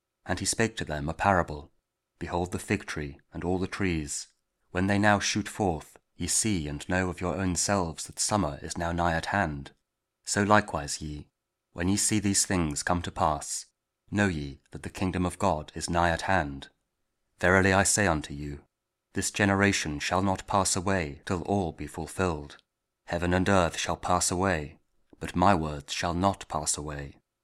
Luke 21: 29-33 – Week 34 Ordinary Time, Friday (Audio Bible KJV, Spoken Word)